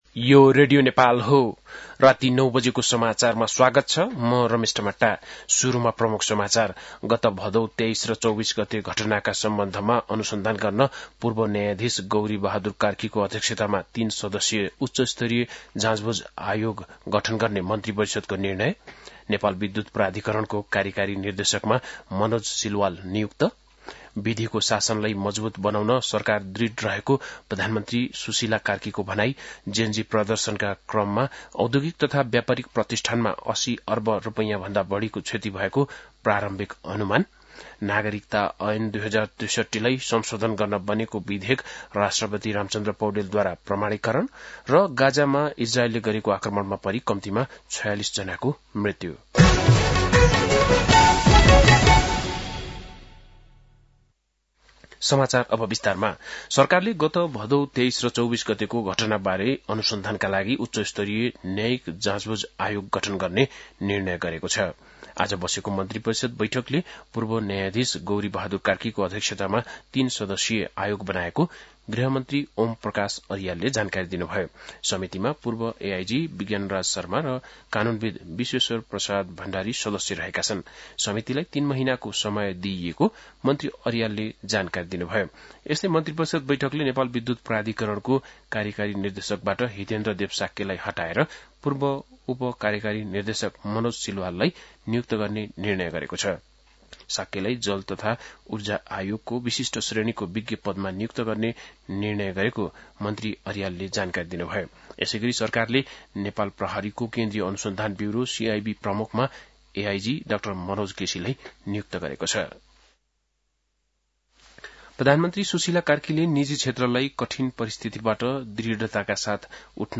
बेलुकी ९ बजेको नेपाली समाचार : ५ असोज , २०८२
9-pm-nepali-news-1-2.mp3